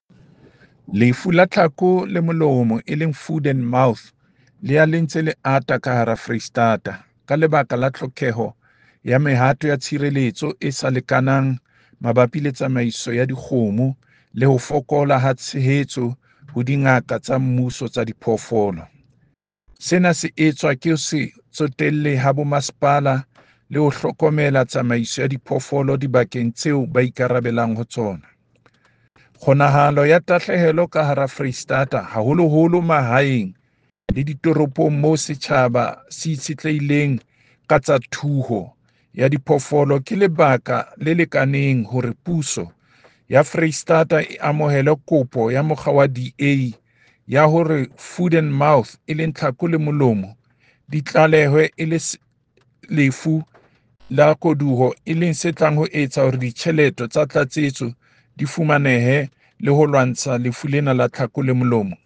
Sesotho soundbite by David Masoeu MPL